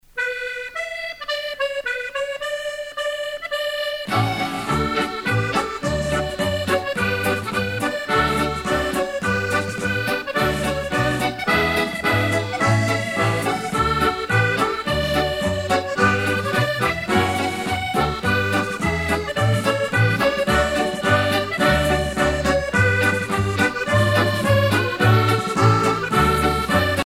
danse : set dance (Irlande)
Pièce musicale éditée